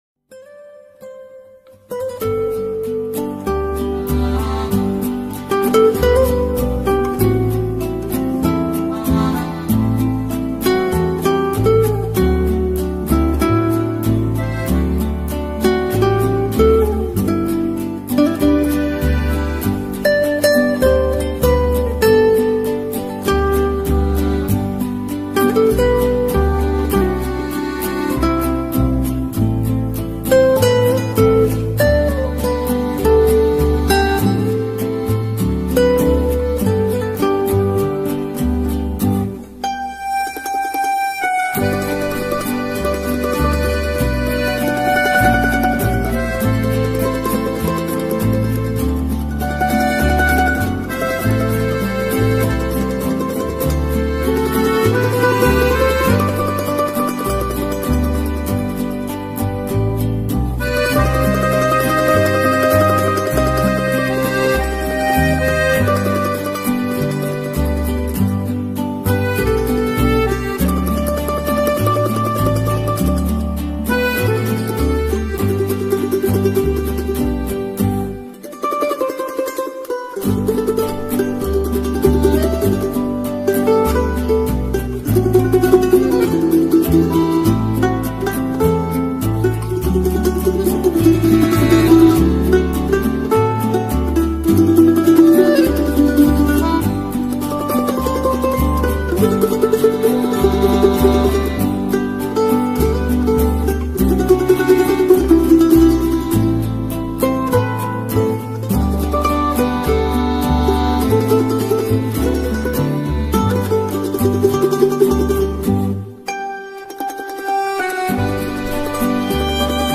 Italian-Music-Background-Chill-Out.mp3